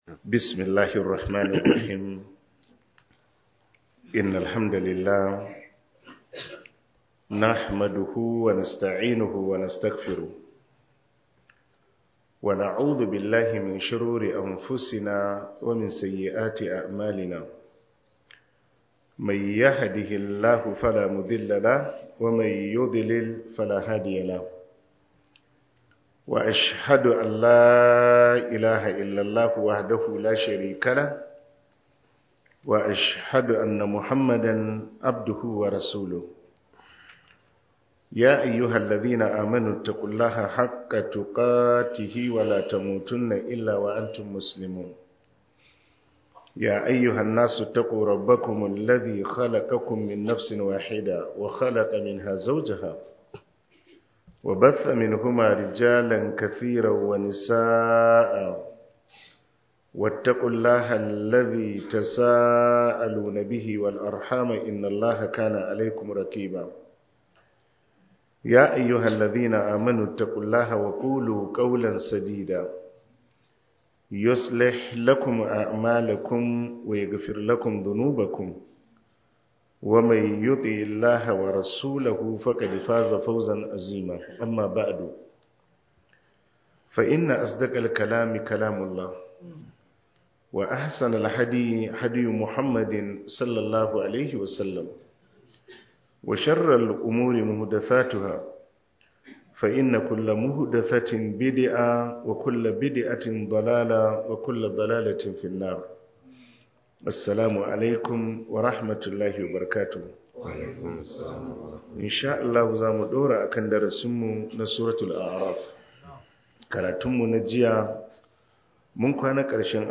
← Back to Audio Lectures 10 Ramadan Tafsir Copied!